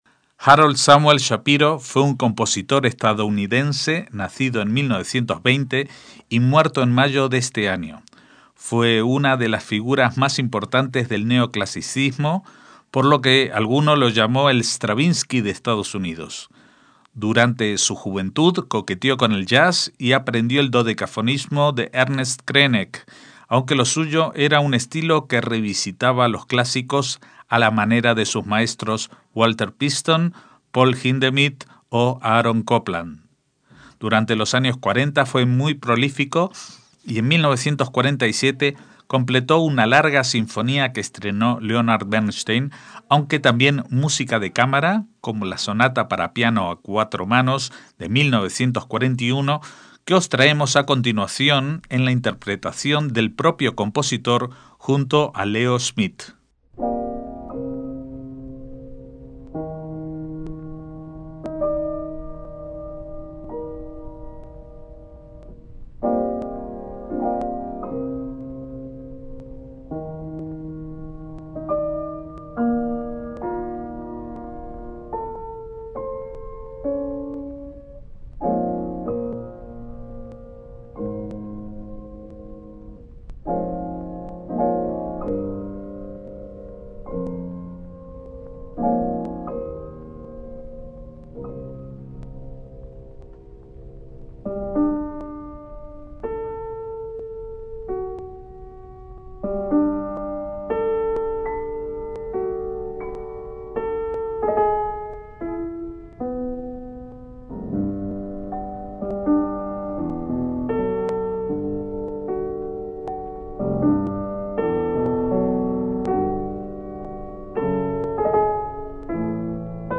MÚSICA CLÁSICA
Escuchamos varias de sus obras para piano